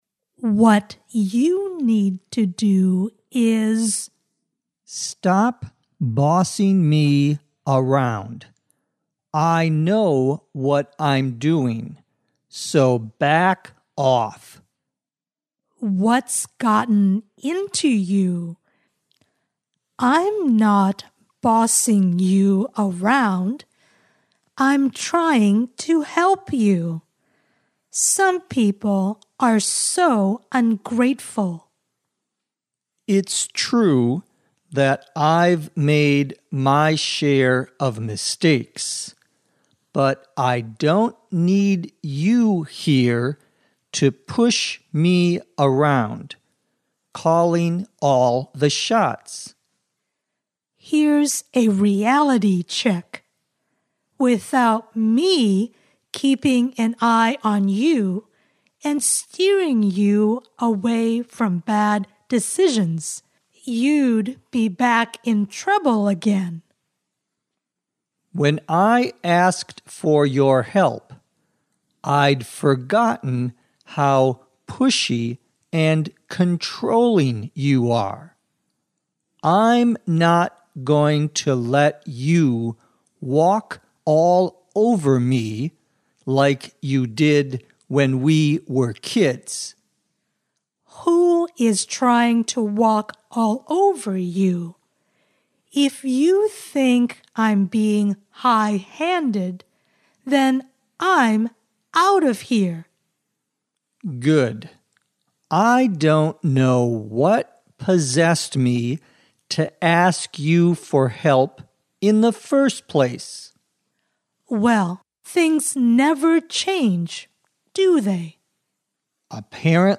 地道美语听力练习:如何应对别人的颐指气使